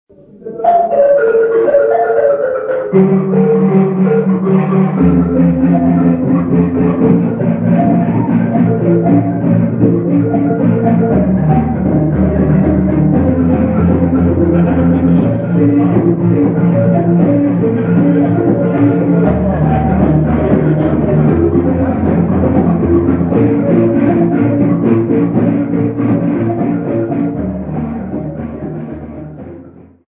Zingisa Seminary Congregation
Folk music
Sacred music
Field recordings
Catholic mass hymn, accompanied by the marimba.